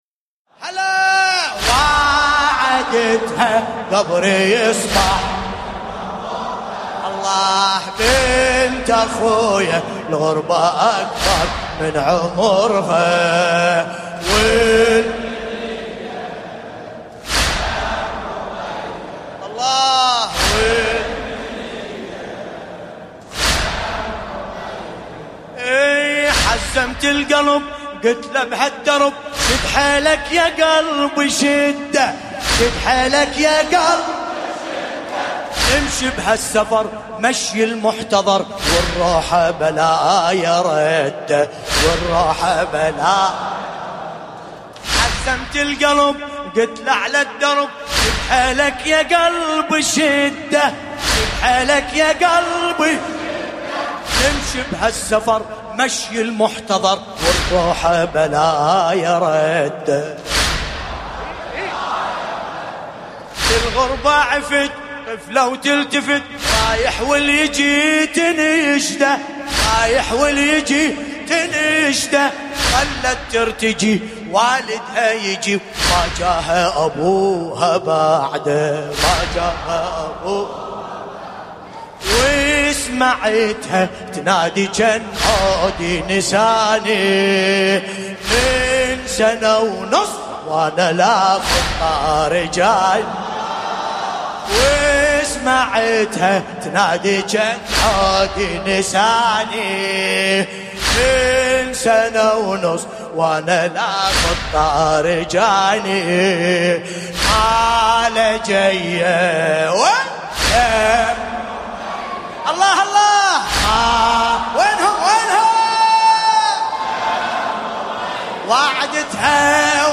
المناسبة : ليالي الزينبية١٤٤٠